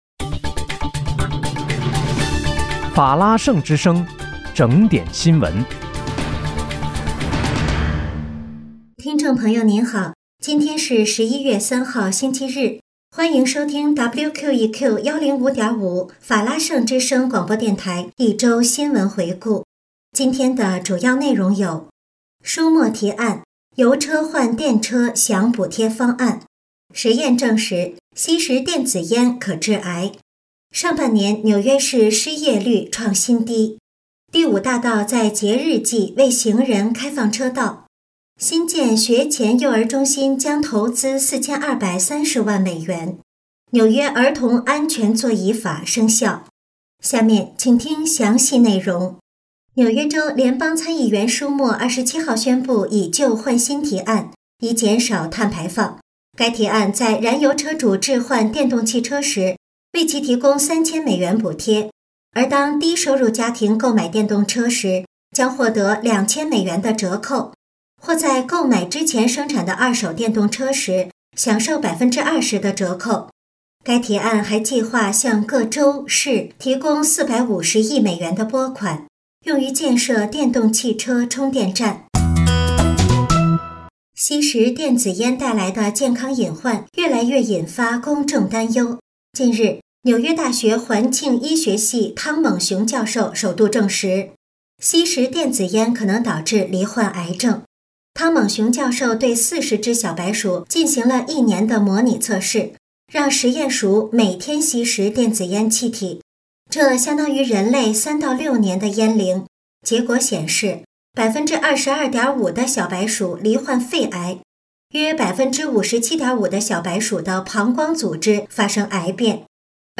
11月3号（星期日）一周新闻回顾